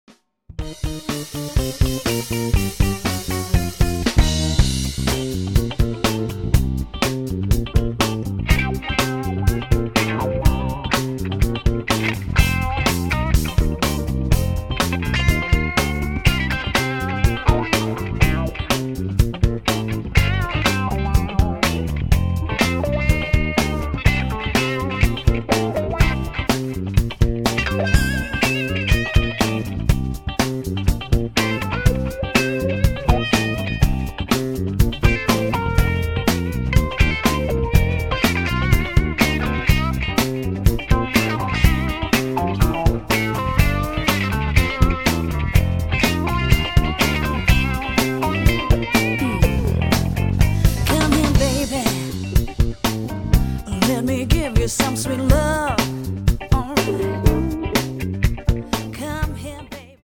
cajon & drums